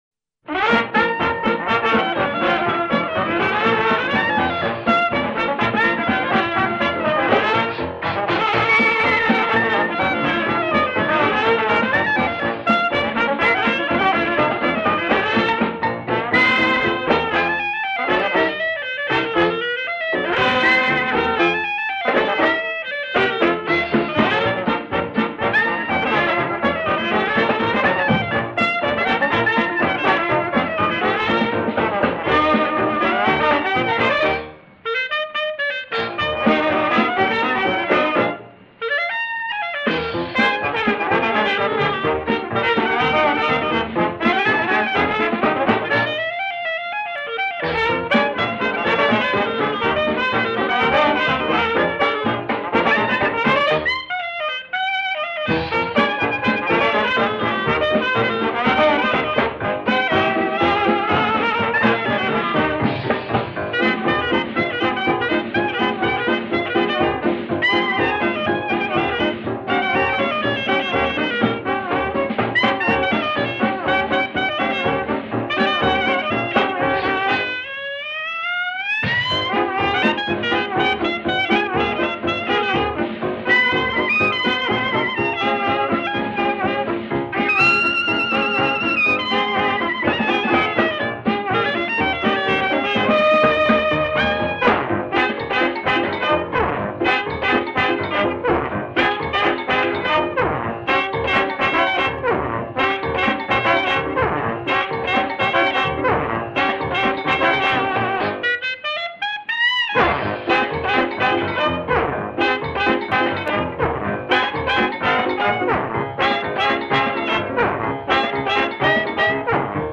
DIXIELAND JAZZ (1910s)